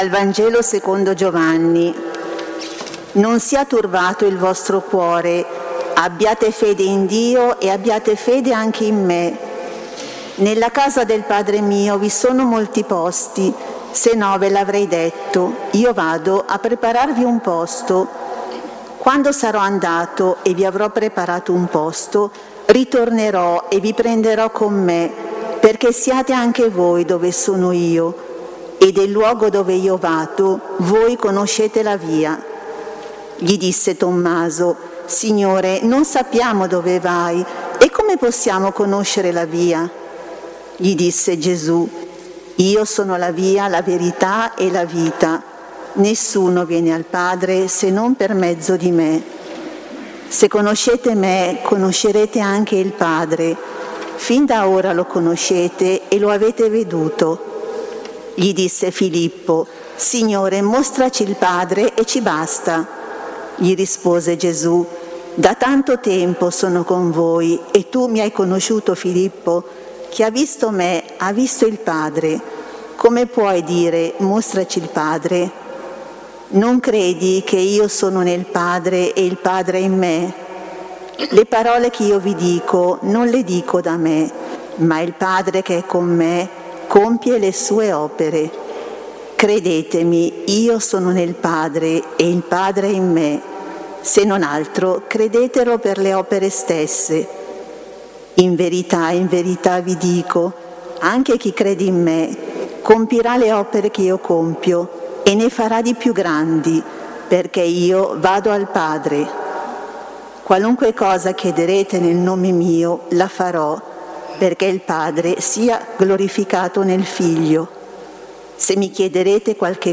Domenica 16 gennaio 2022ore 16.00 | Lectio divina a Giussano